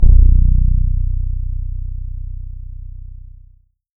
SYN FRETLE-L.wav